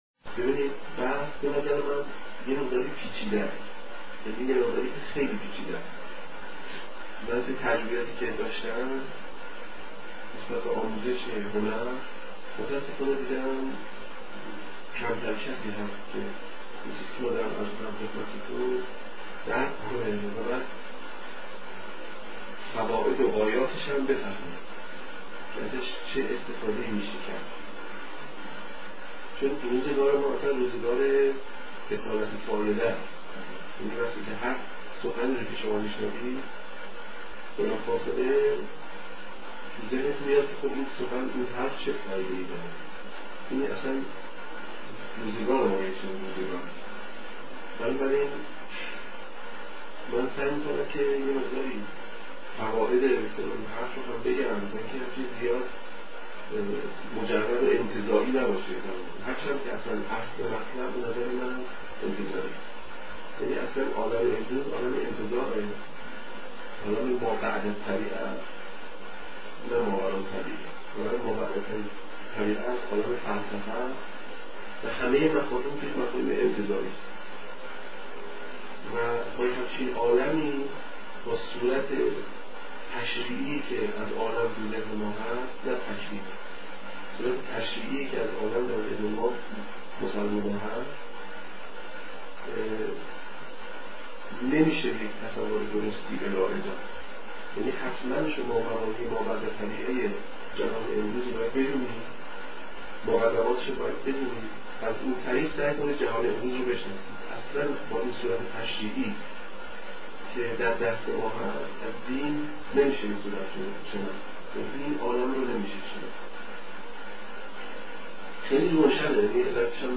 صوت روایتگری